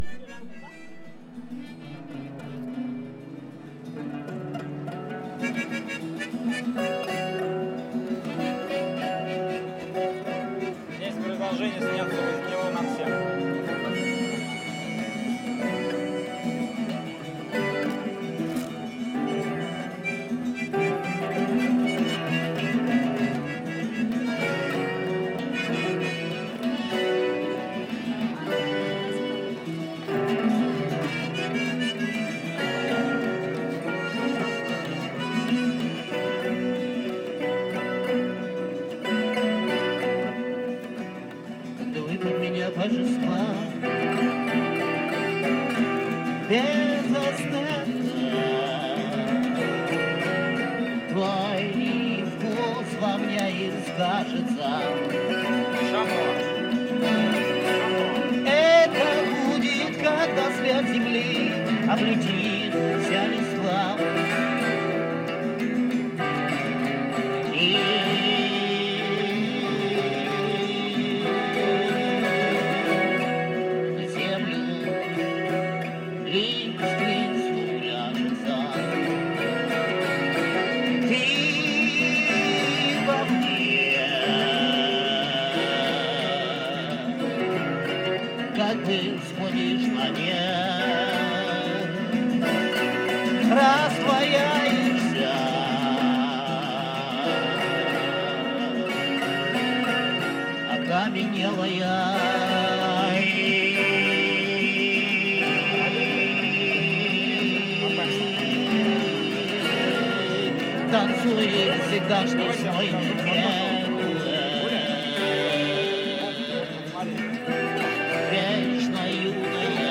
Motard russie
Samara, Russie
motard-russe-rue.mp3